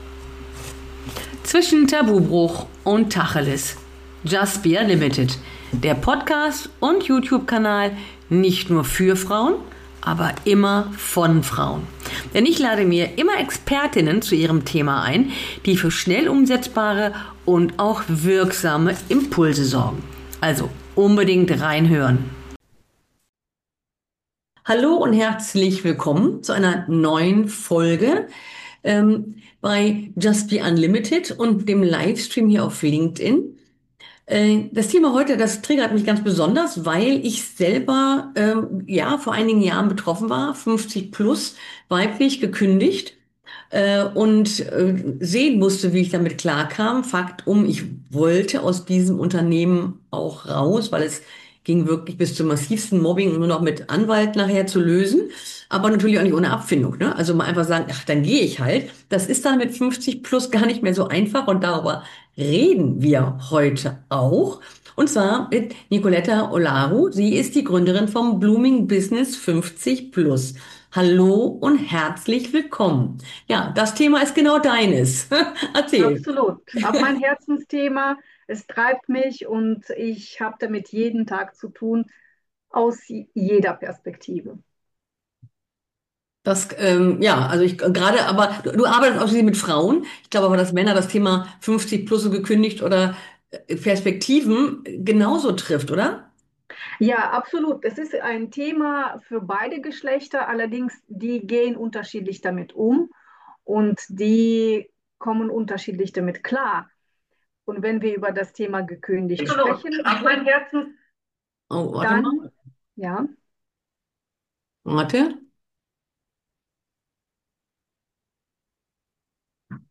In diesem Talk reden wir darüber und bieten Lösungen!